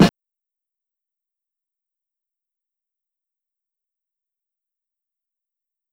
Snare (CHUM).wav